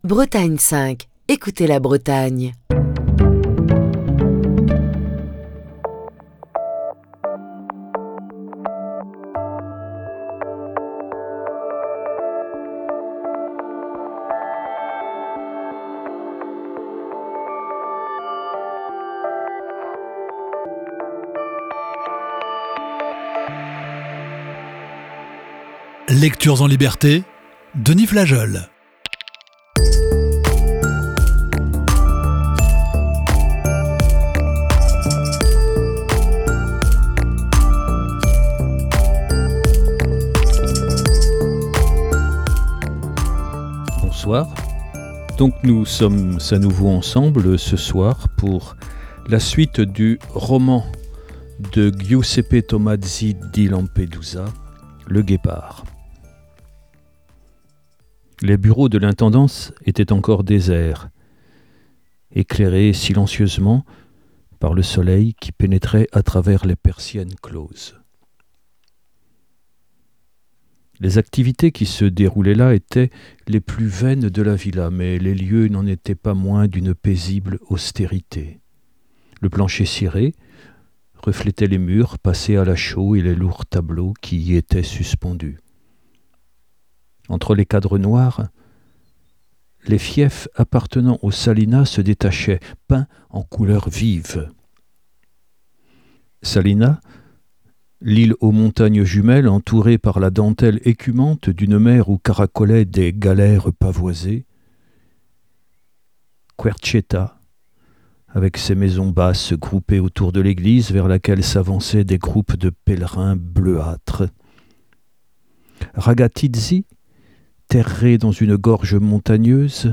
la lecture du célèbre roman